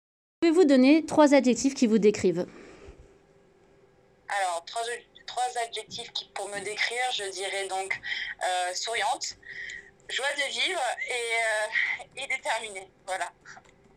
Interview :